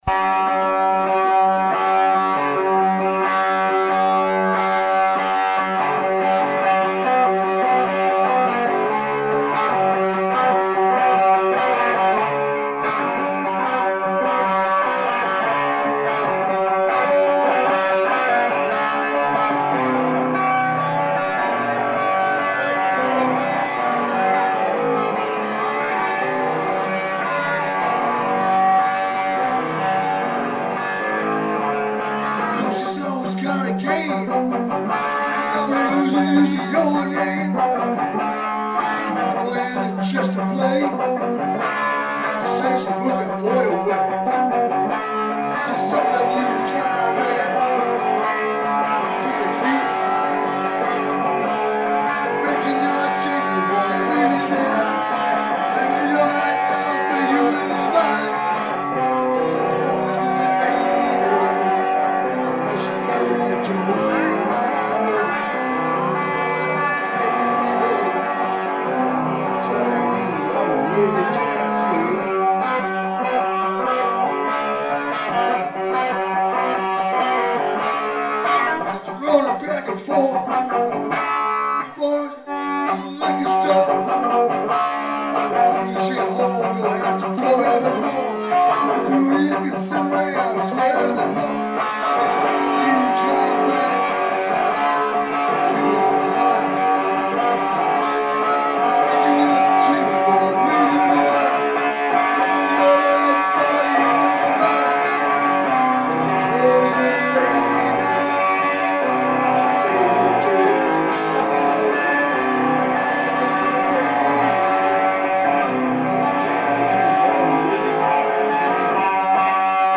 Hardcore
guest guitarist